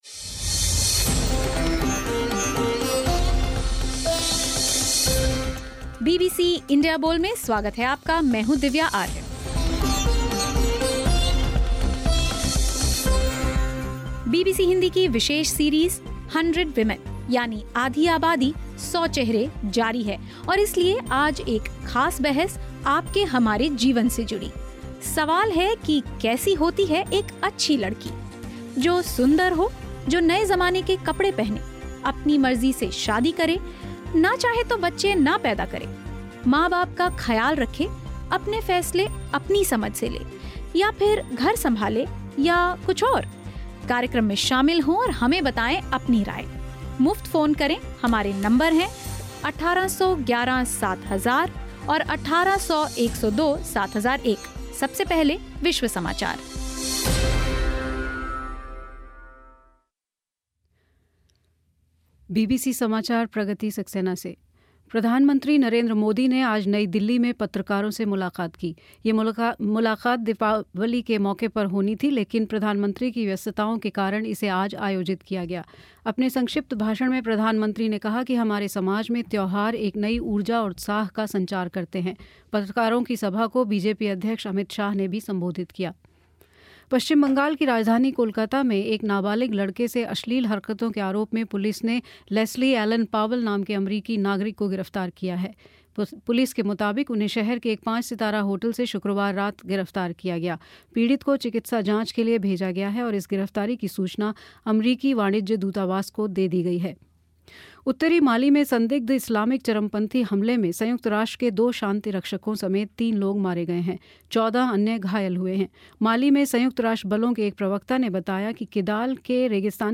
कार्यक्रम में इसी बहस को सुनने के लिए क्लिक करें.